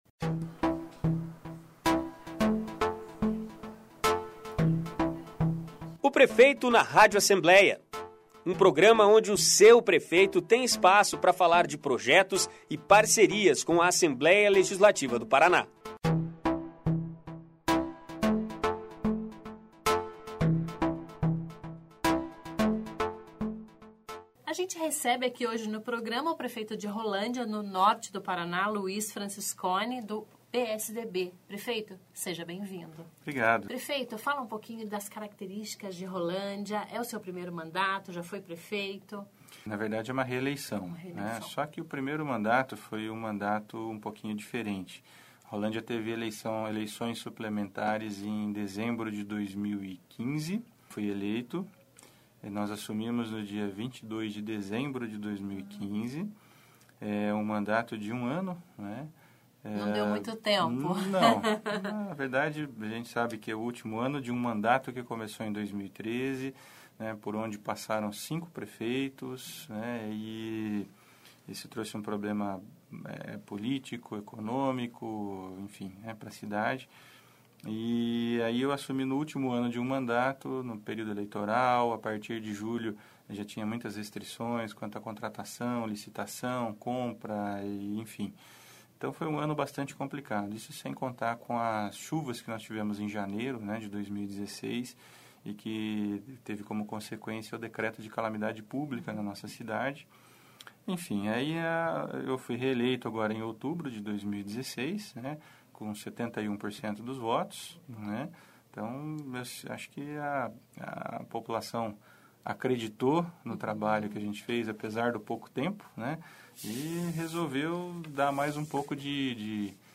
Ouça entrevista com prefeito de Rolândia, Luiz Francisconi.